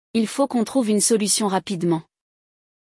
Aqui, você aprende francês na prática, ouvindo diálogos do dia a dia e expandindo seu vocabulário sem precisar sair da sua rotina.